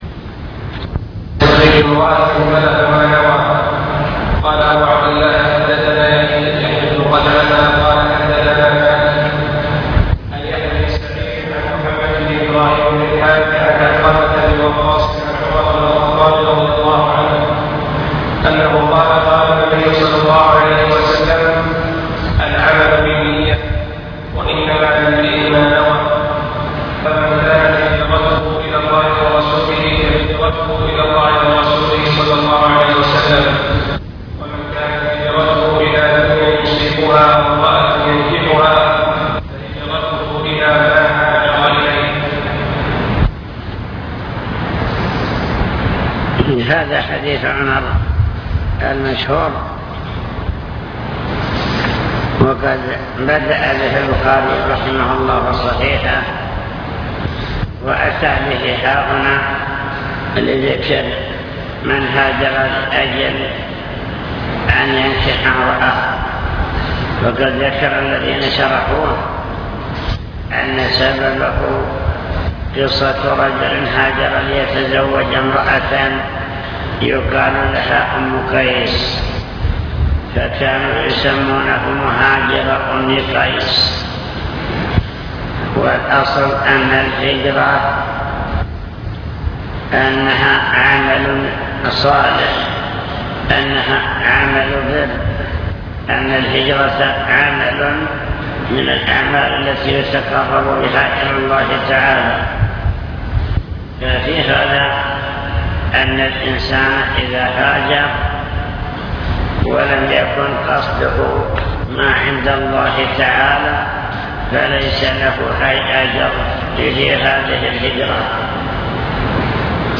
المكتبة الصوتية  تسجيلات - كتب  شرح كتاب النكاح من صحيح البخاري